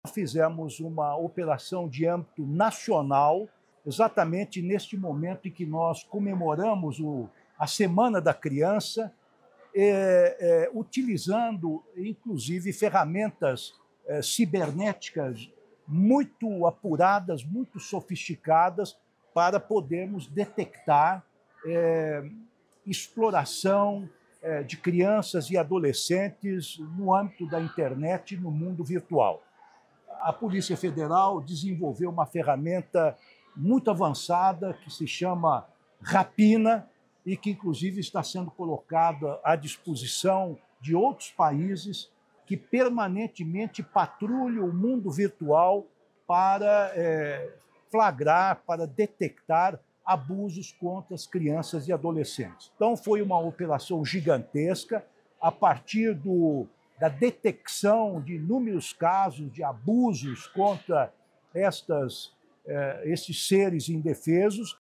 Ministro Ricardo Lewandowski fala sobre a Operação Nacional Proteção Integral III — Ministério da Justiça e Segurança Pública